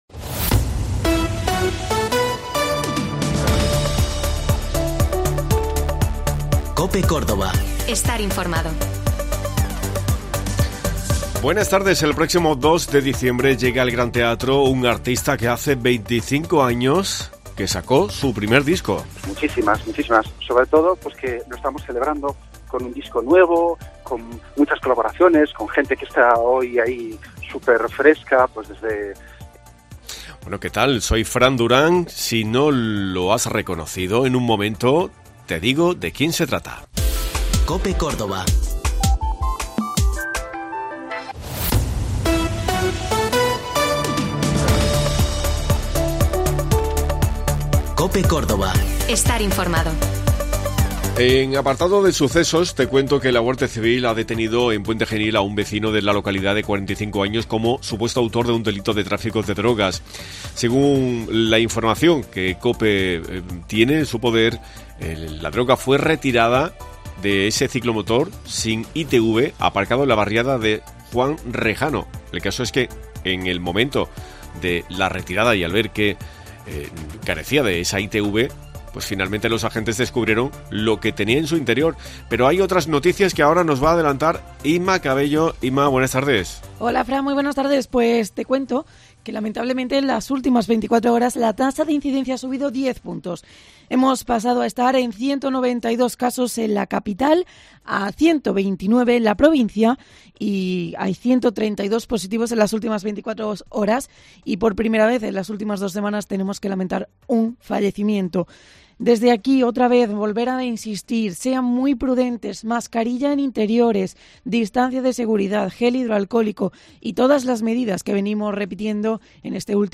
Hoy hemos hablado con Carlos Núñez que llegará al Gran Teatro de Córdoba el próximo 2 de diciembre 2021 a las 20:00 horas.